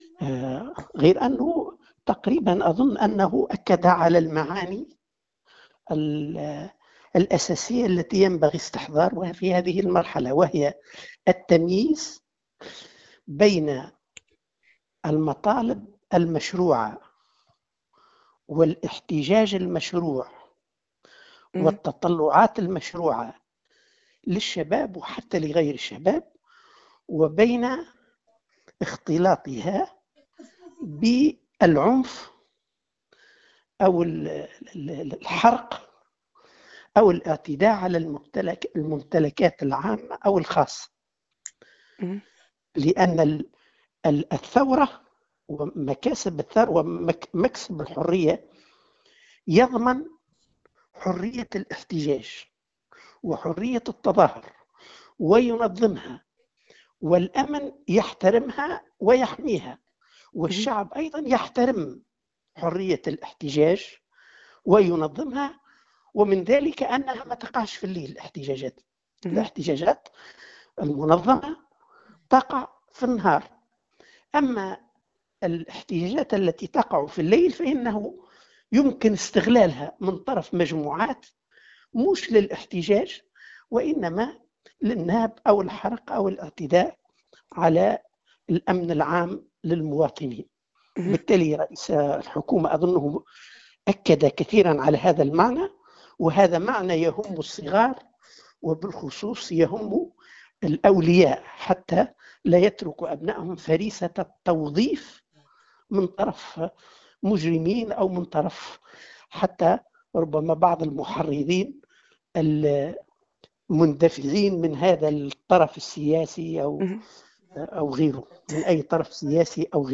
Dans une déclaration accordée à Tunisienumérique le 19 janvier 2020, Larayedh a souligné qu’il faut distinguer entre les protestations pacifiques, motivées par des revendications légitimes et entre les actes de violence et de criminalité qui ne cherchent que la destruction.